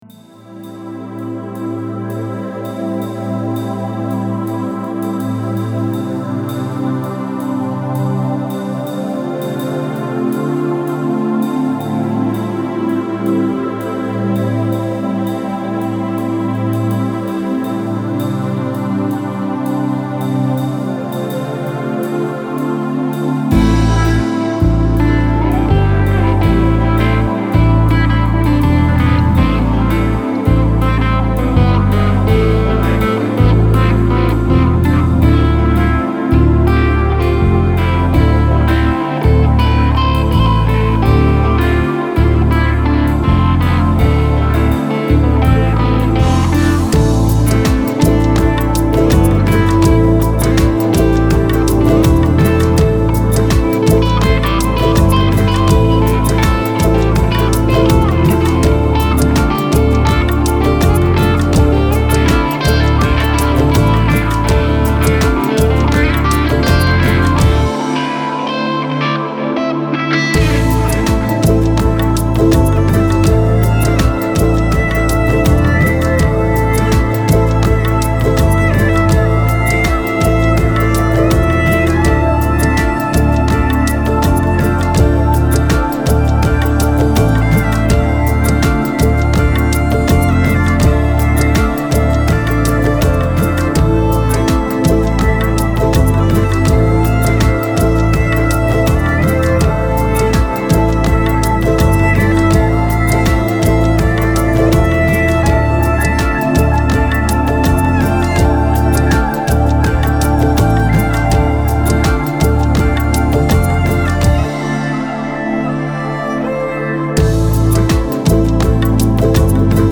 Медитативная музыка Нью эйдж Релакс New Age Музыка релакс